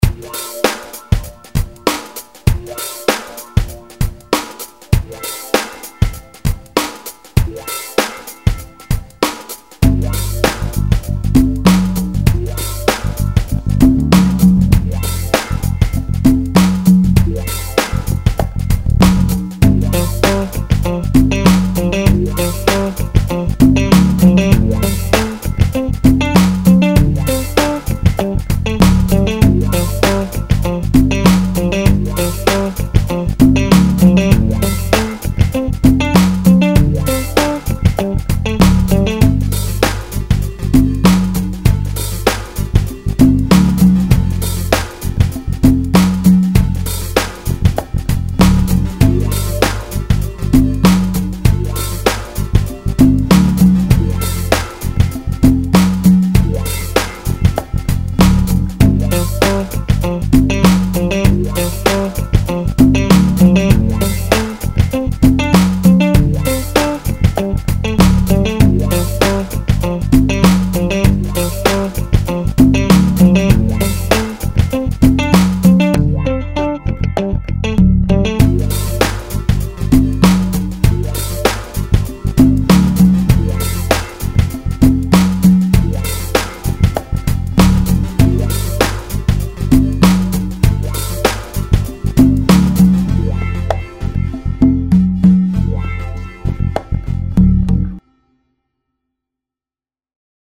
lounge - chill - detente - aerien - guitare